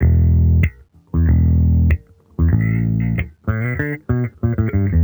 Index of /musicradar/sampled-funk-soul-samples/95bpm/Bass
SSF_JBassProc2_95E.wav